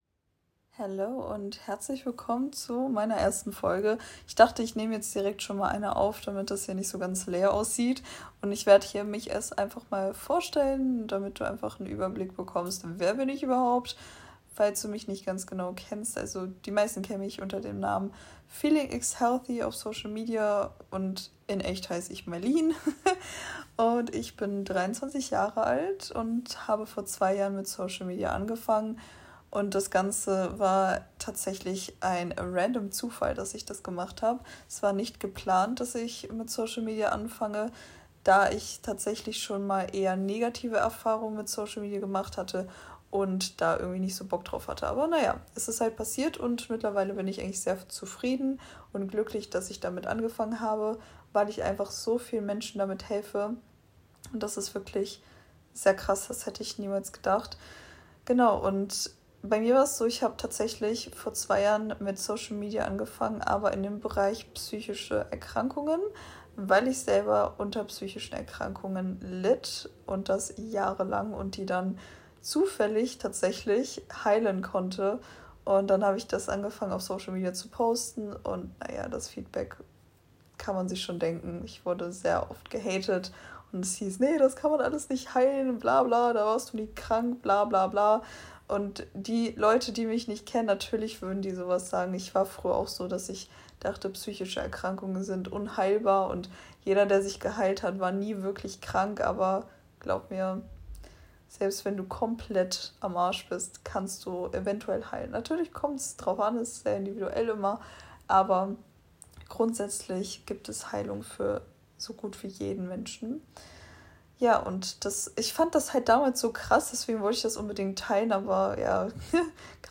Hier erzähle ich komplett uncut kurz wer ich bin und gebe mein